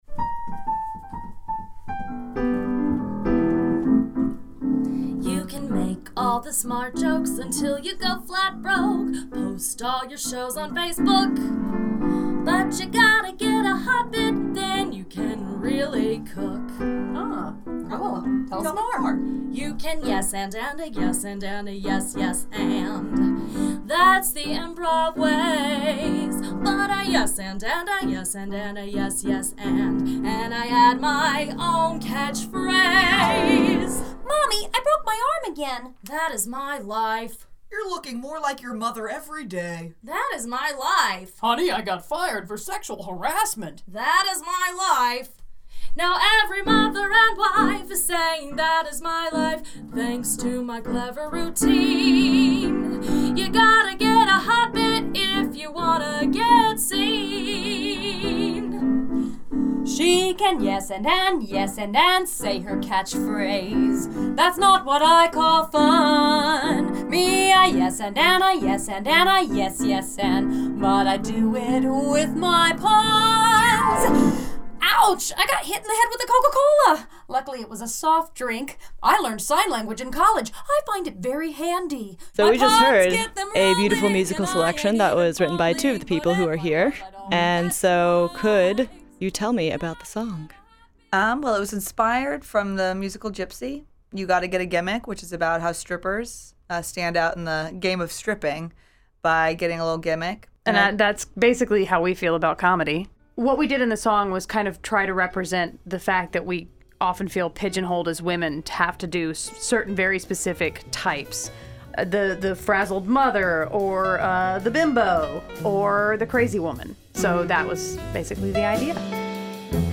Broadcast version of this interview
What do you get when you have four comedians in a recording studio? Far too much.
Between jokes about oatmeal and cat ladies, four local comedic talents came into WABE’s studio to talk about Atlanta’s comedy scene and what it means for female comedians.